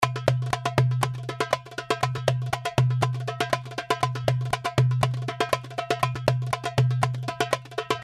120 BPM malfuf and baladi darbuka (29 variations)
This package contains real darbuka loops in malfuf and baladi beat, playing at 120 bpm.
The darbuka was recorded with vintage neumann u87 in a dry room by a professional Darbuka player.
The darbuka is in mix mode(no mastering,no over compressing). There is only light and perfect analog EQ and light compression, giving you the The opportunity to shape the loops in the sound you like in your song.